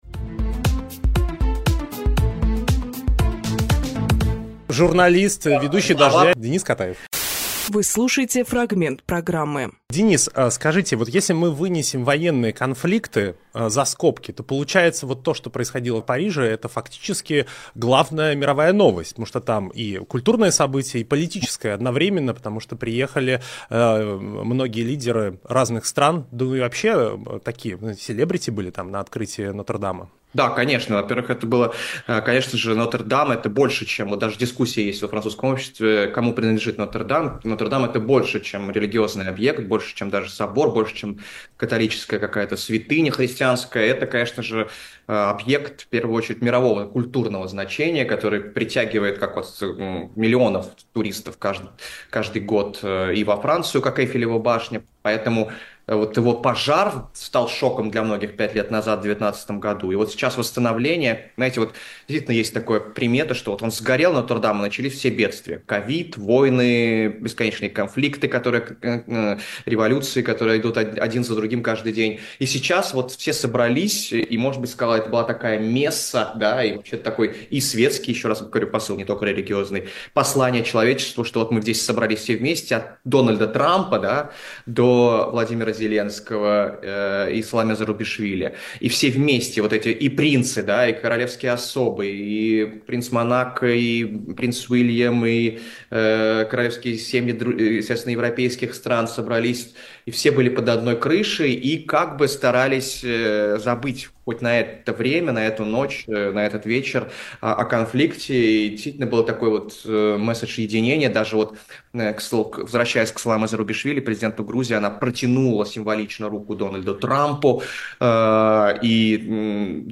Фрагмент эфира от 08.12